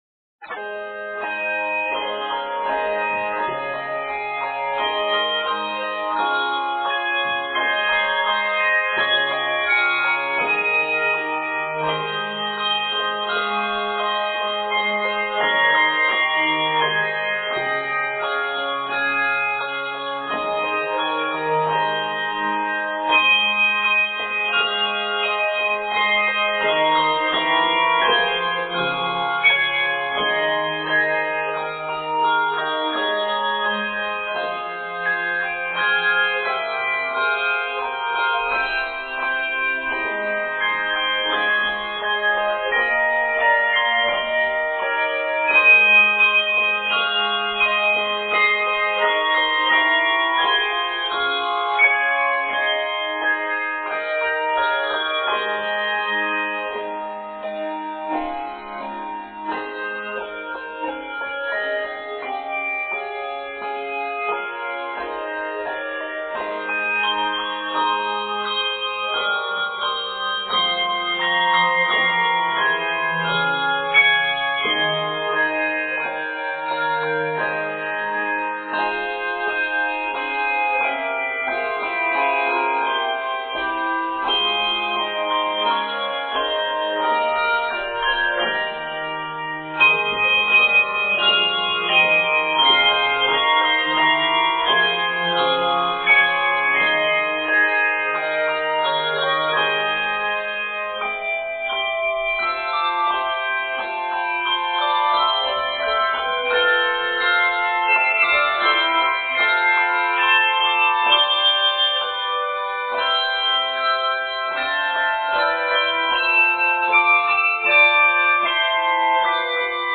IT is mostly chordal.